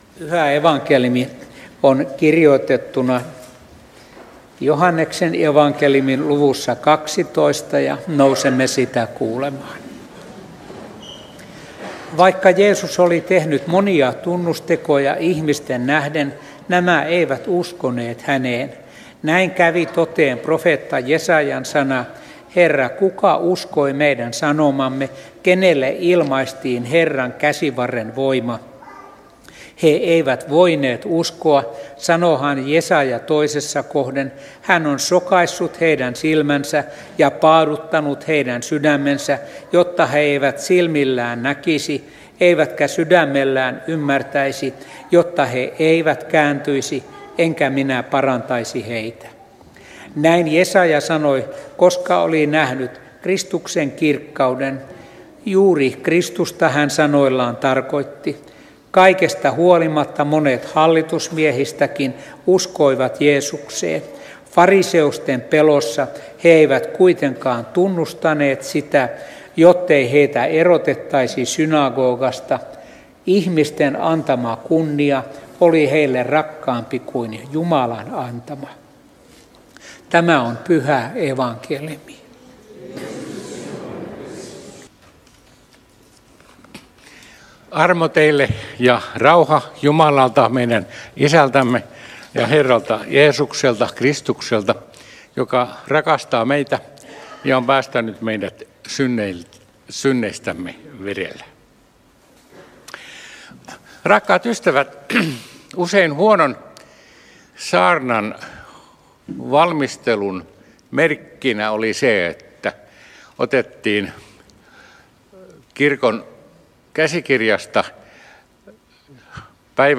saarna Turussa 3. paastonajan sunnuntaina Tekstinä Joh. 12:37–43